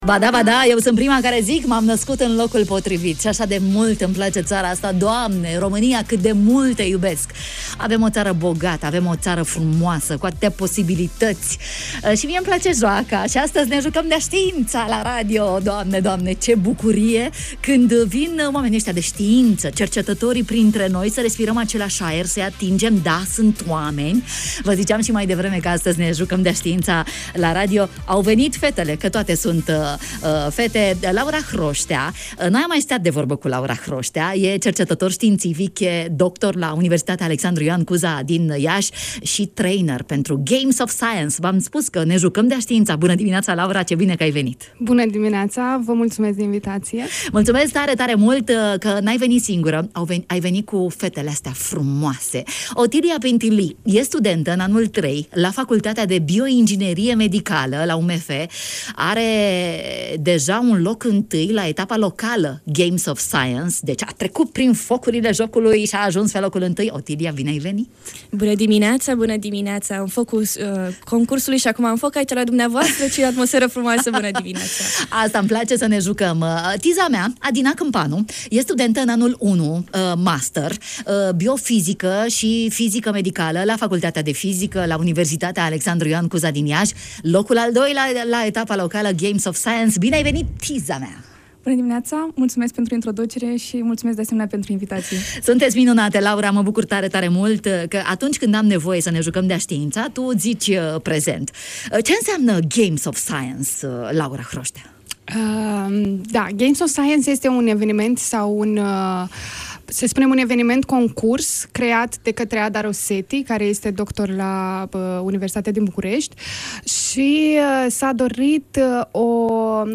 Câștigătorii concursului ”Games of Science” în direct la matinalul de la Radio Iași - Radio Iaşi – Cel mai ascultat radio regional - știri, muzică și evenimente